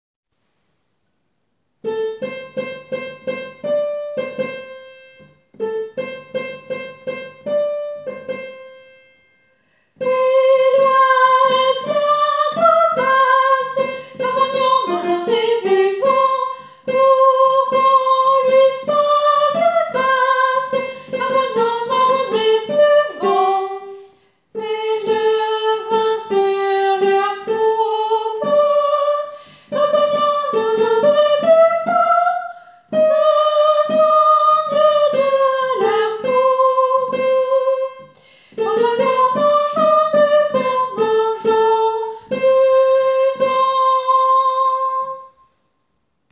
Ténor :
Loie-ténor.wav